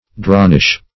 Search Result for " dronish" : The Collaborative International Dictionary of English v.0.48: Dronish \Dron"ish\, a. Like a drone; indolent; slow.